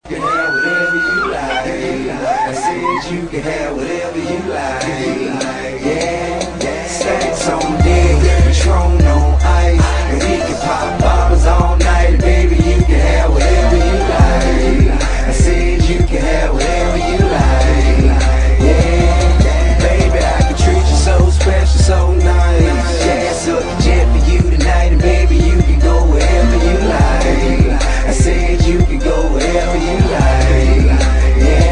• Rap Ringtones